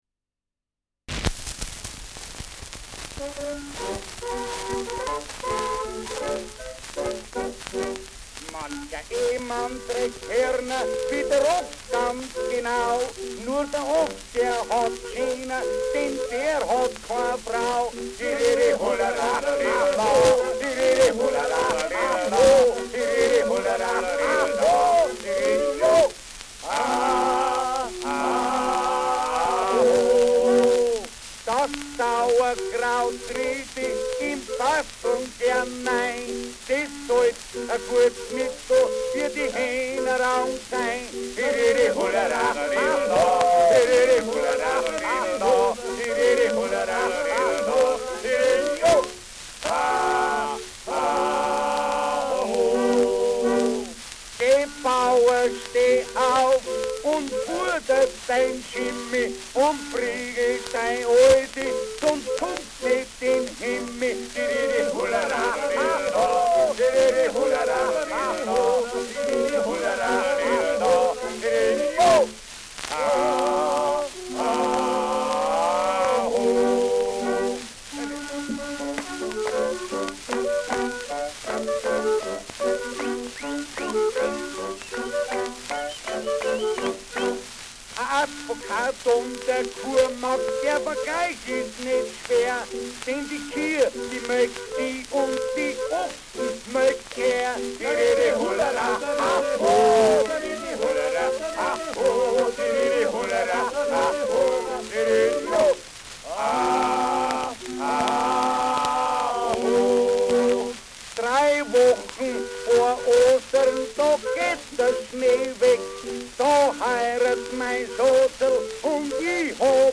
Schellackplatte
Stark abgespielt : Erhöhtes Grundrauschen : Verzerrt an lauten Stellen : Gelegentlich leichtes bis stärkeres Knacken
Adams Bauern-Trio, Nürnberg (Interpretation)
[Nürnberg] (Aufnahmeort)